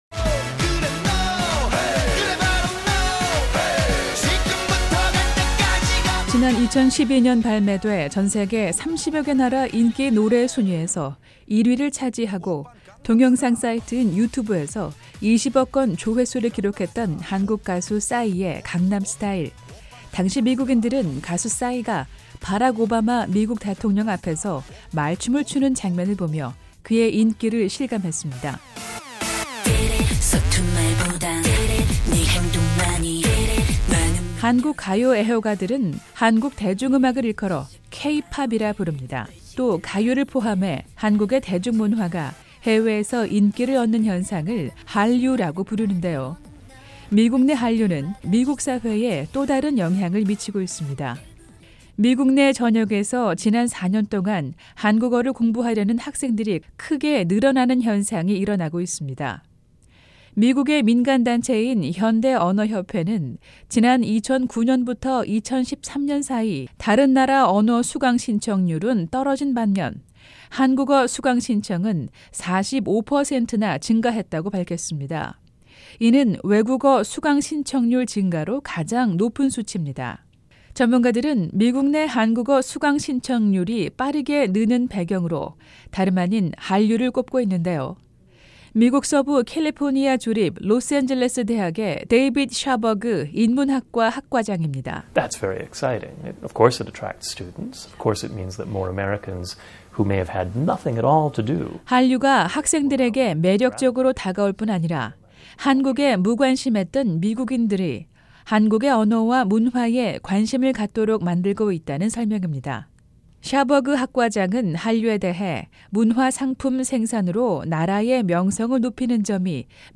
매주 화요일 화제성 뉴스를 전해 드리는 `뉴스 투데이 풍경'입니다. 미국 대학에서 한국어의 인기가 점차 높아지고 있는 것으로 나타났습니다.